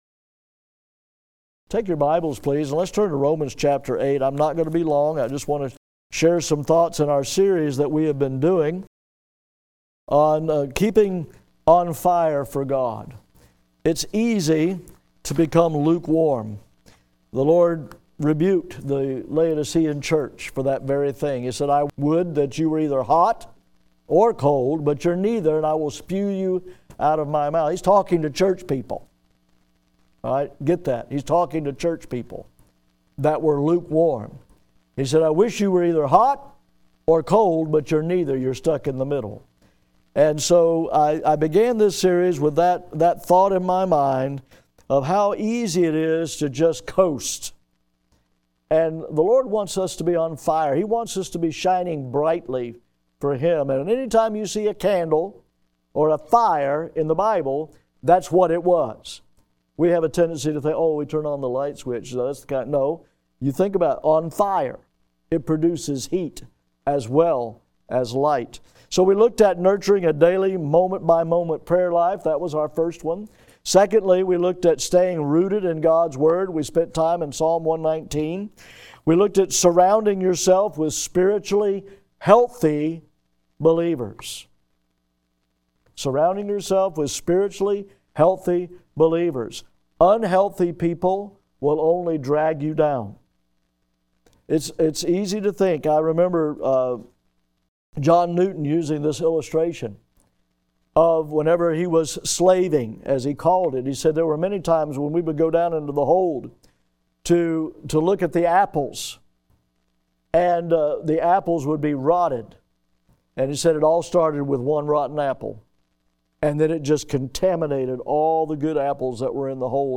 GRACE BIBLE CHURCH Audio Sermons
The preaching at Grace Bible Church in Milton, Florida is available in audio sermons on our website.